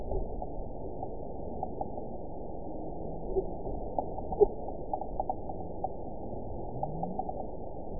event 912558 date 03/29/22 time 13:16:06 GMT (3 years, 1 month ago) score 9.59 location TSS-AB05 detected by nrw target species NRW annotations +NRW Spectrogram: Frequency (kHz) vs. Time (s) audio not available .wav